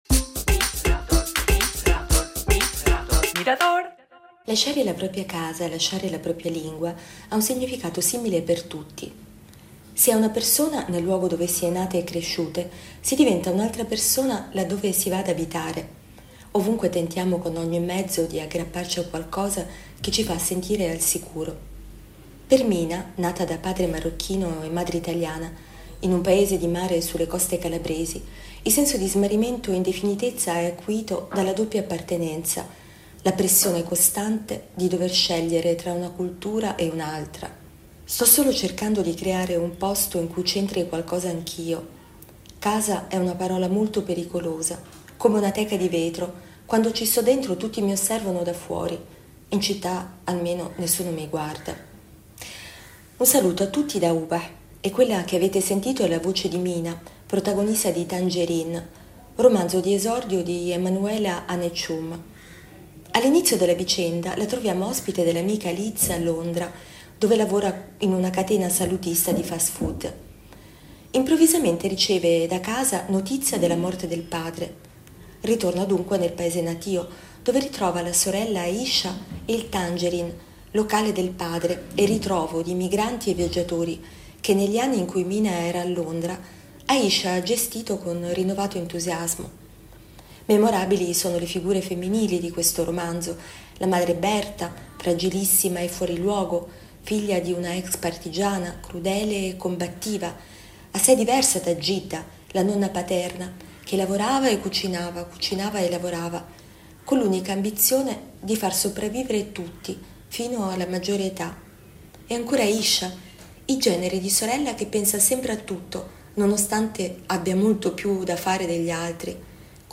Libro recensito